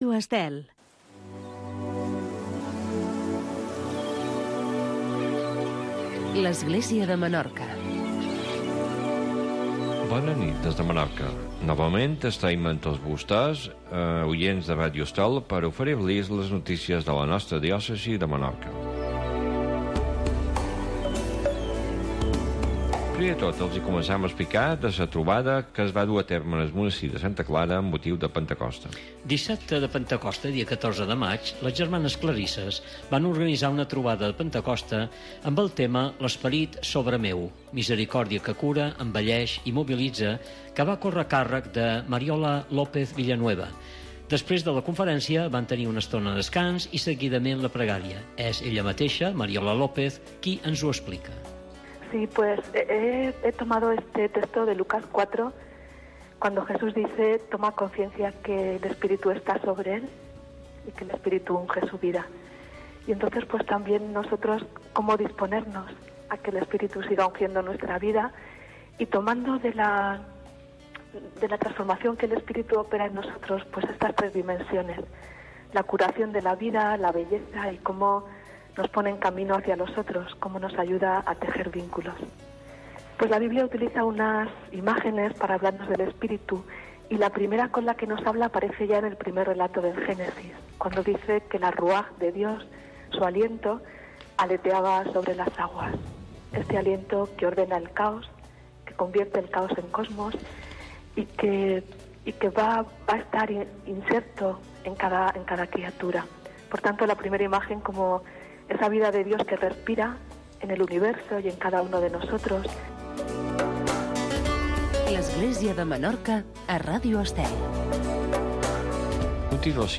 Informatiu d’actualitat cristiana del bisbat de Menorca.